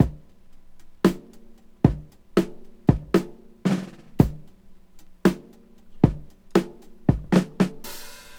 Tuned drums (C# key) Free sound effects and audio clips
• 57 Bpm Drum Groove C# Key.wav
Free breakbeat - kick tuned to the C# note. Loudest frequency: 525Hz
57-bpm-drum-groove-c-sharp-key-AhK.wav